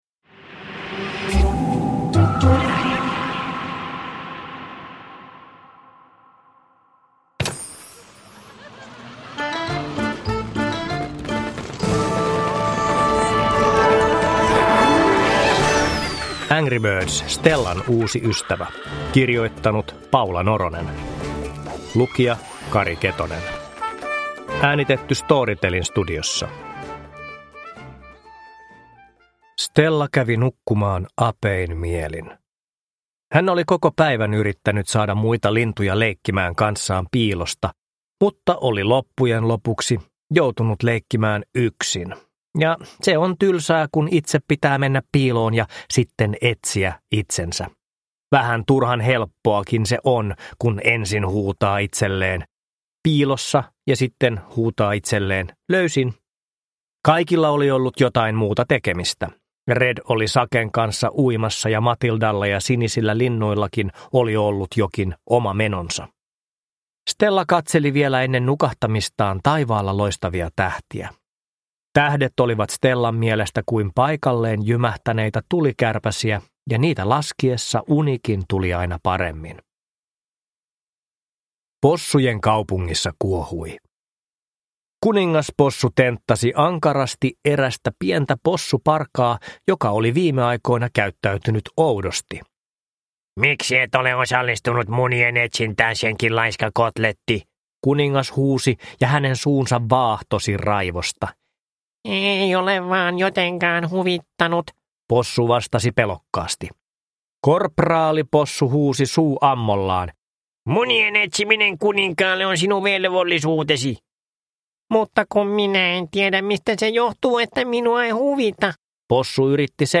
Produkttyp: Digitala böcker
Uppläsare: Kari Ketonen